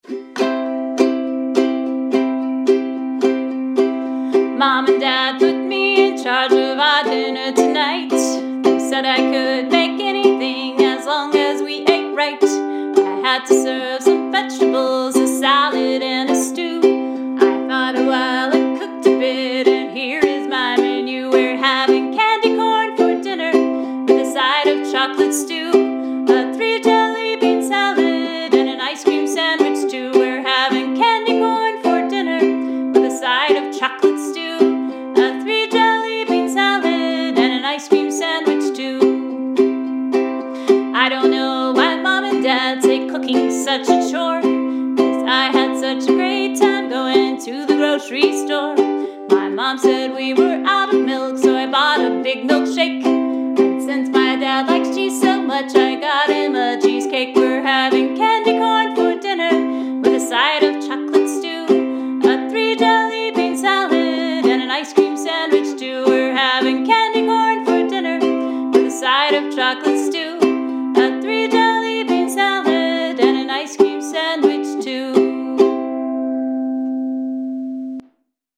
Candy Corn for Dinner: I wrote this song several years ago for an Ice Cream Storytime, and it’s a fun one to have the kids play along to with maracas and egg shakers.